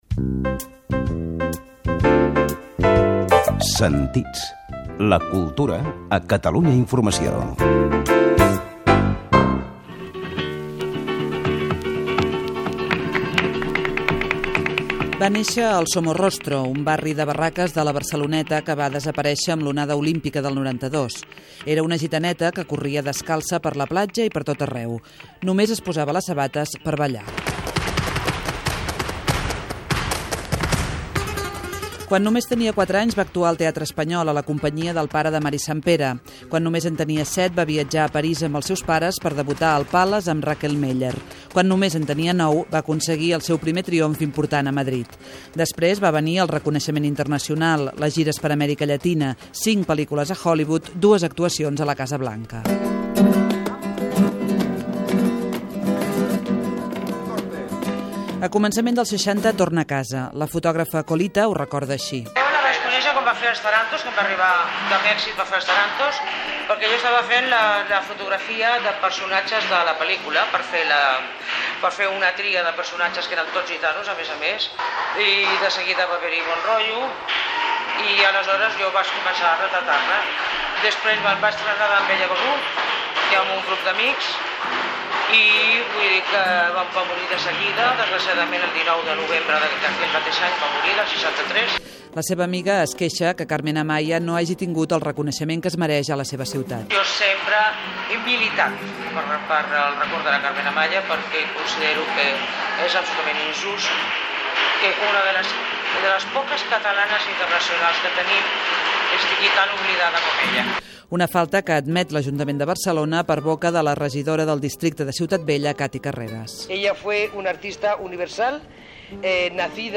Careta del programa, reportatge sobre Carmen Amaya, dades biogràfiques i artístiques de la balladora flamenca. Inclou testimonis de la fotògrafa Colita, la regidora Cati Carreras.